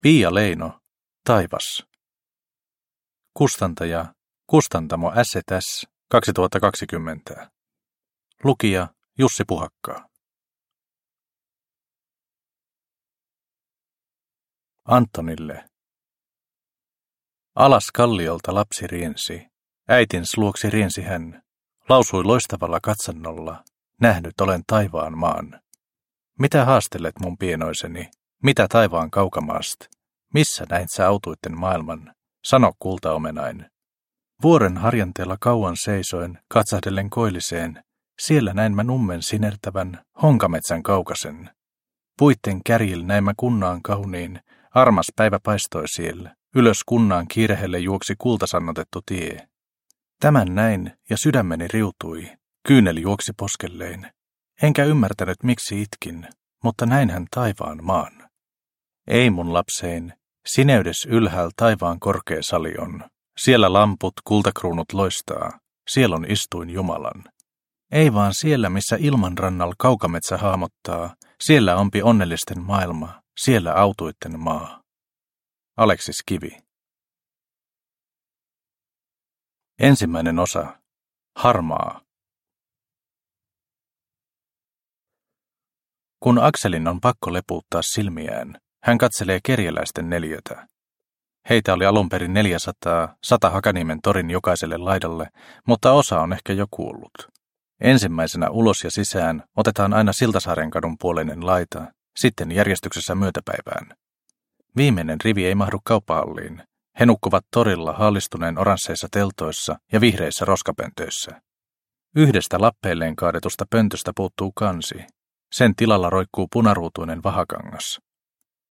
Taivas – Ljudbok – Laddas ner